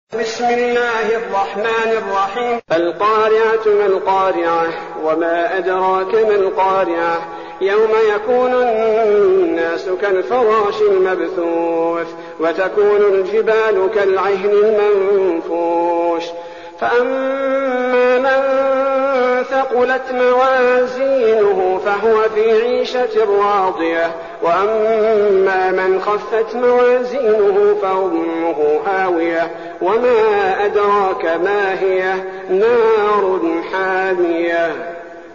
المكان: المسجد النبوي الشيخ: فضيلة الشيخ عبدالباري الثبيتي فضيلة الشيخ عبدالباري الثبيتي القارعة